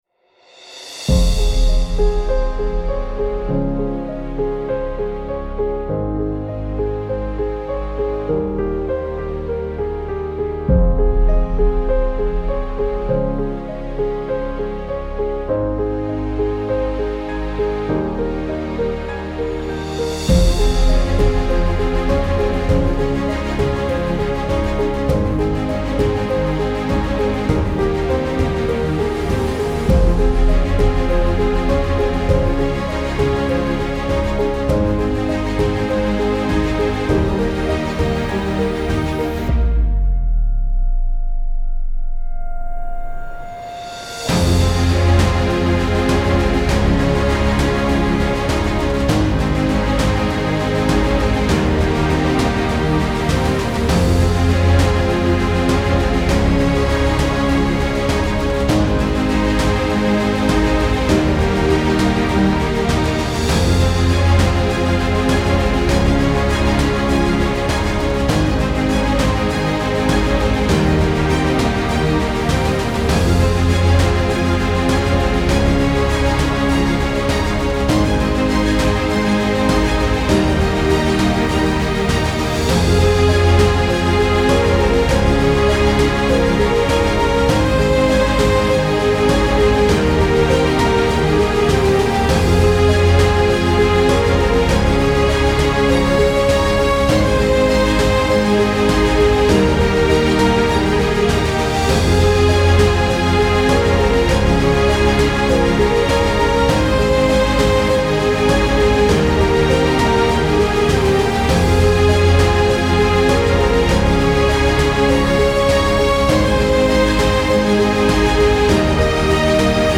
Even without lyrics, the song still conveys a clear message.
Ringtone